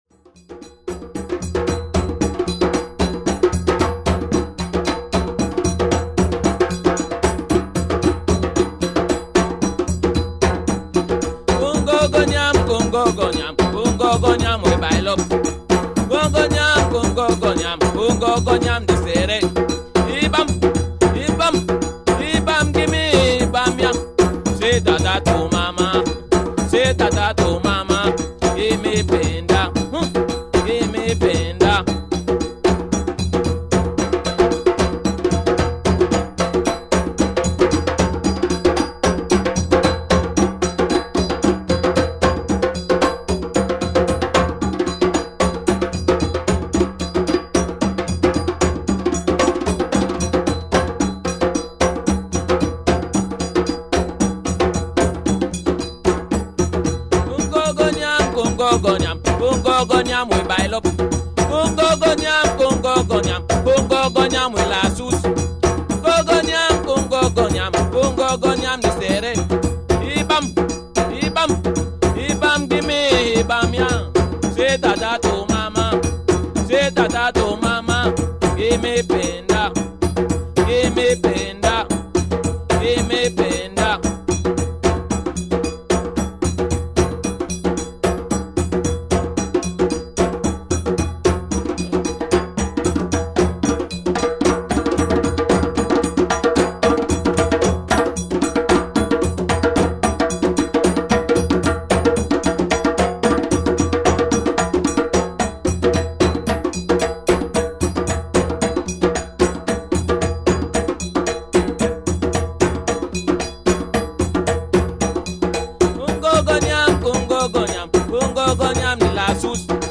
traditional song and rhythm